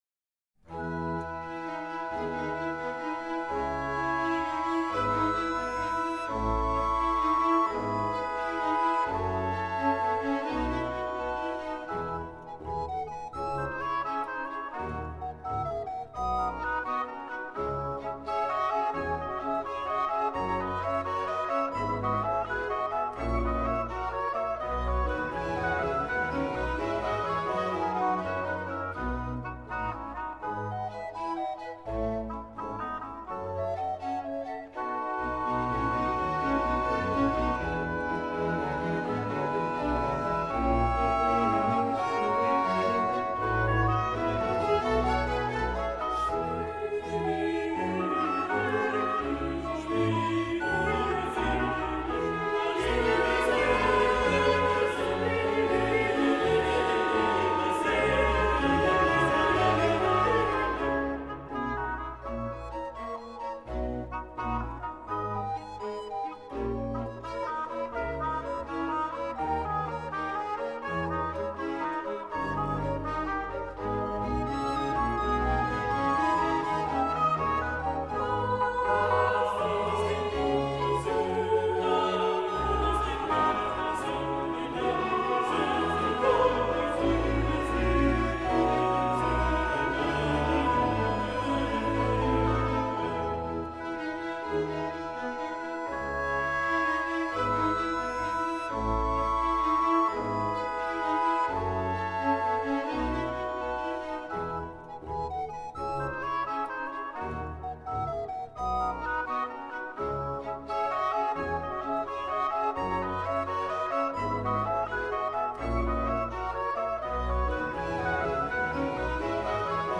1 . Chœur
Flûtes à bec I/II, HautboisI/II (Hautbois da caccia), Violons I/II, Violes, Continuo